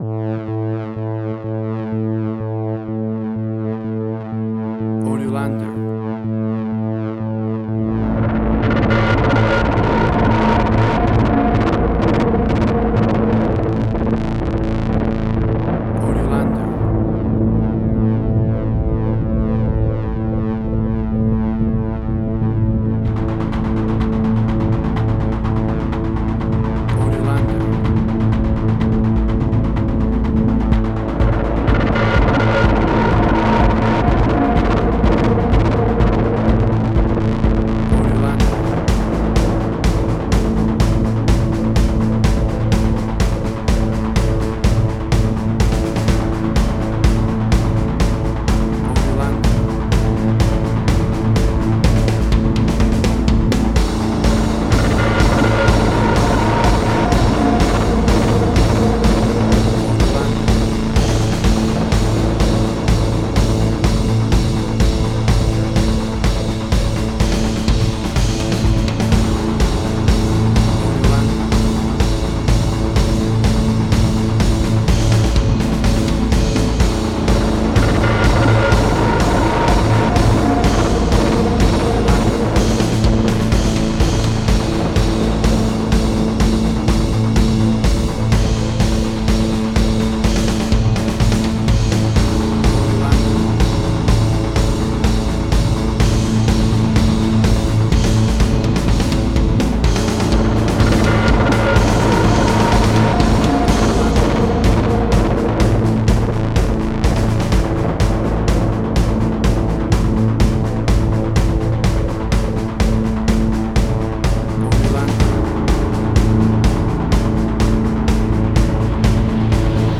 Modern Science Fiction Film, Similar Tron, Legacy Oblivion.
Tempo (BPM): 125